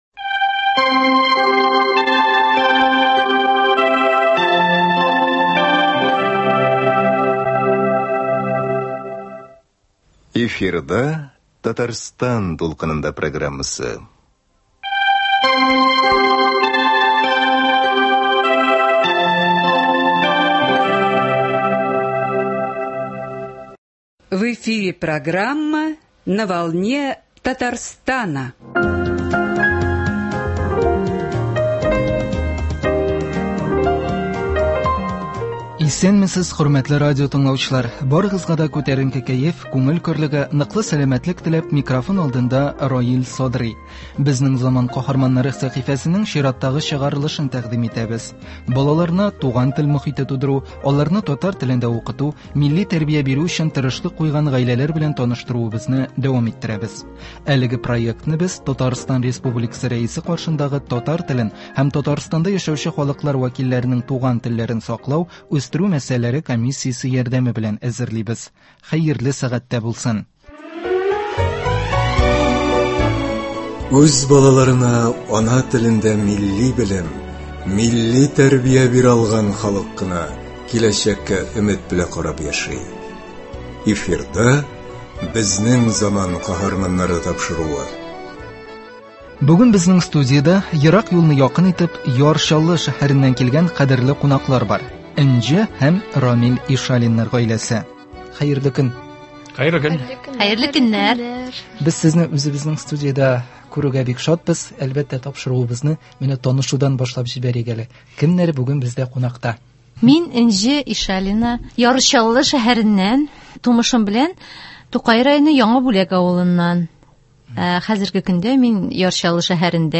Бүген безнең студиядә ерак юлны якын итеп, Яр Чаллы шәһәреннән килгән кадерле кунаклар бар.